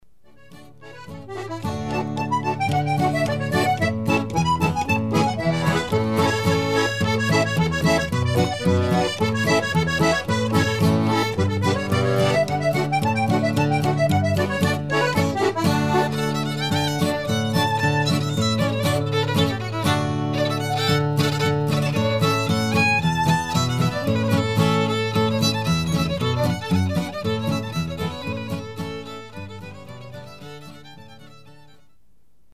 Dalle musiche da ballo della tradizione popolare emiliana,
DEMO mp3 - Frammenti brani registrazione live